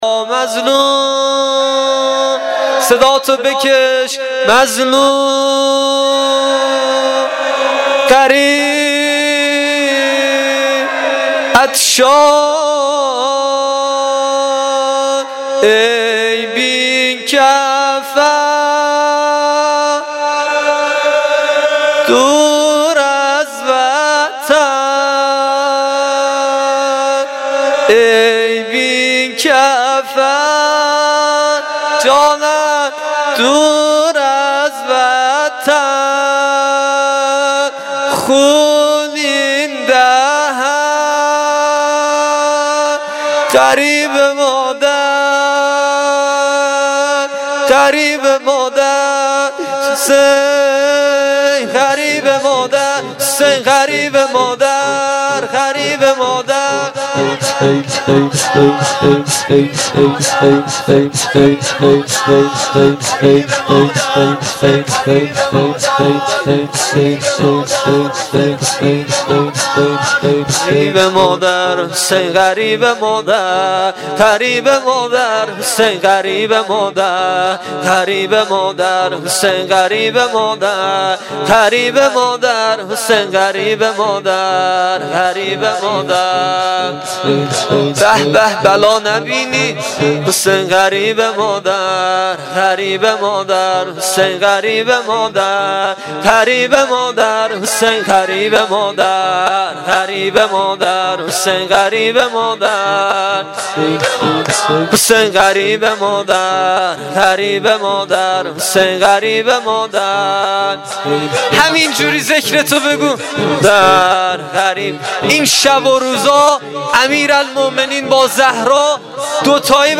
شور شب 21 ماه رمضان